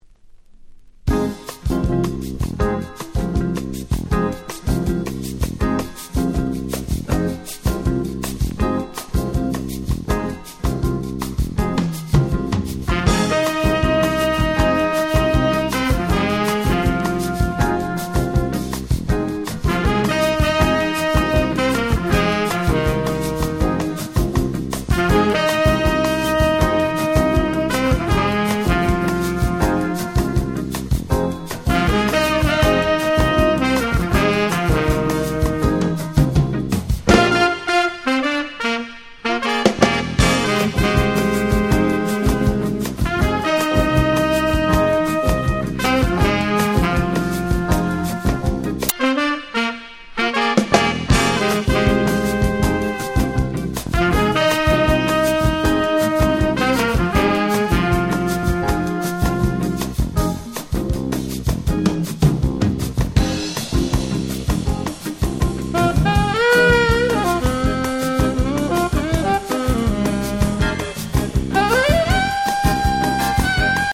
91' Very Nice Acid Jazz 12'' !!
アシッドジャズ サックス